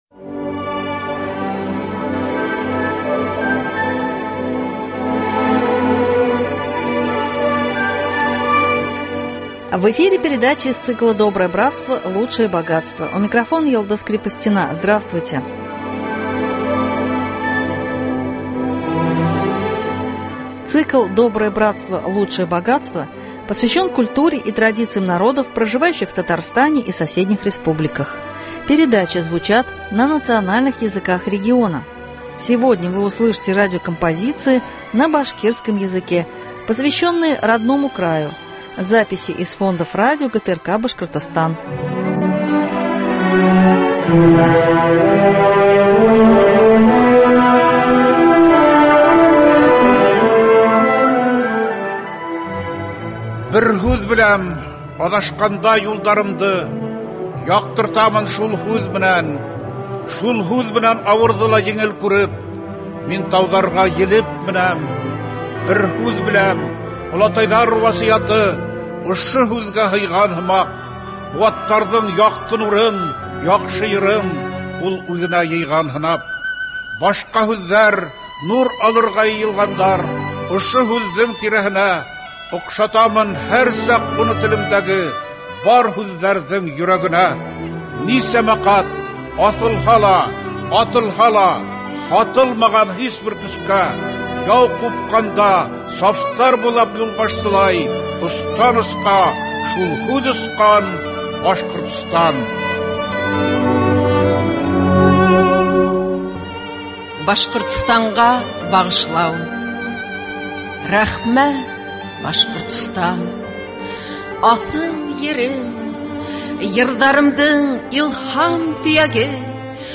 Программа из фондов башкирского радио.